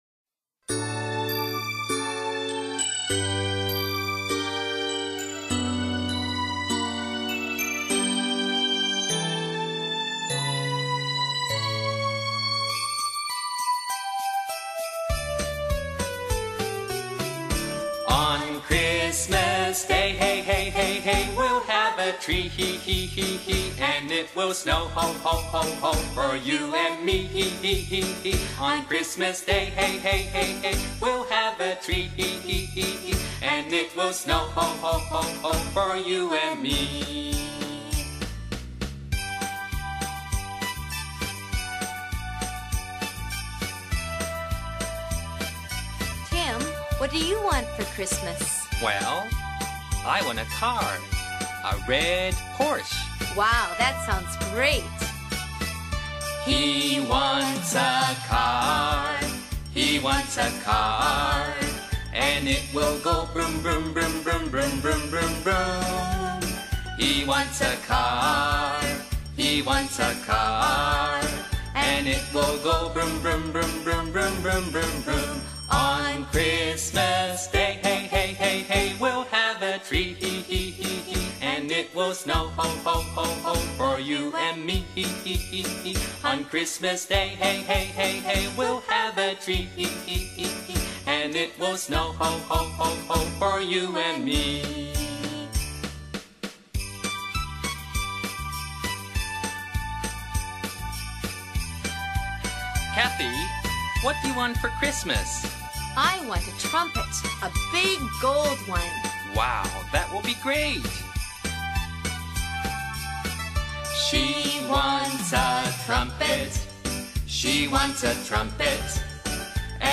どの歌も編曲が明るく楽しいので子供達が喜んで歌ってくれます。